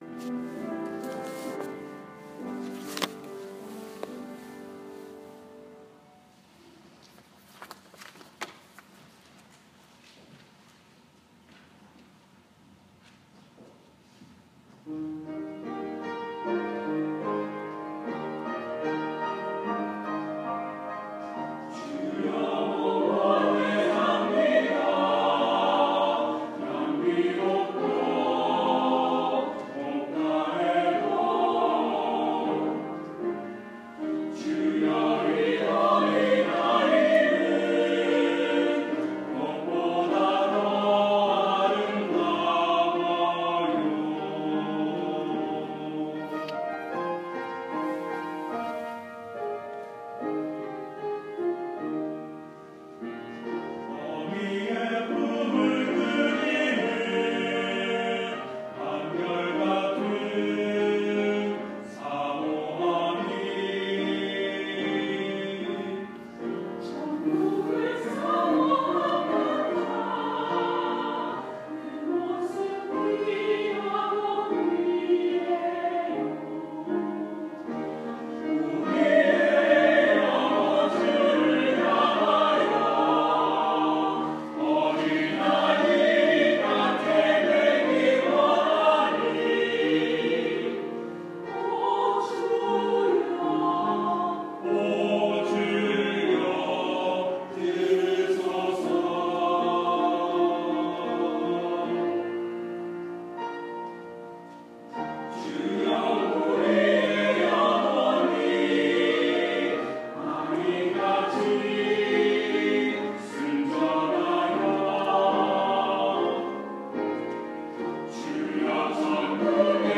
5월 1일 주일 찬양대(오월의 기도, Jay Althous곡)
롱아일랜드(코맥)연합감리교회 5월 1일 어린이주일 찬양대 찬양